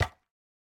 resin_brick_step5.ogg